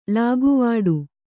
♪ lāguvāḍu